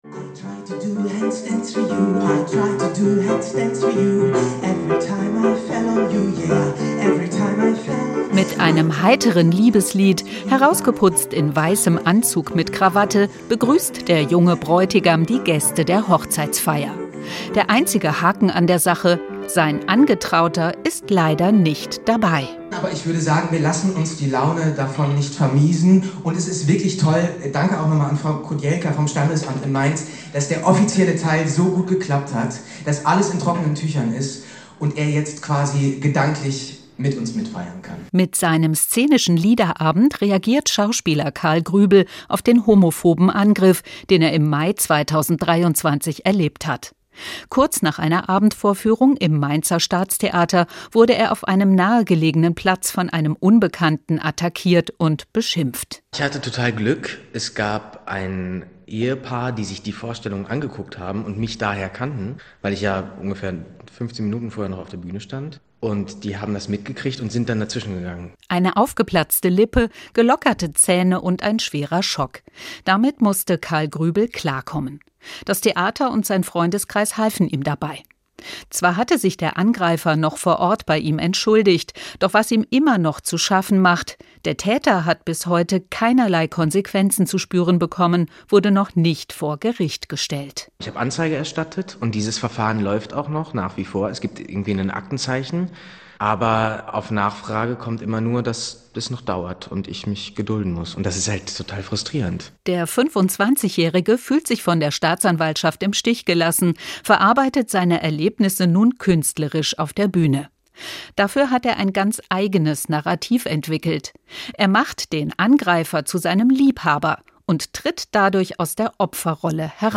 Die Inszenierung verbindet Songtexte, Tagebucheinträge und Spoken Word zu einem „szenischen Liederabend“, der queere Verletzlichkeit und gesellschaftliche Ignoranz gleichermaßen offenlegt.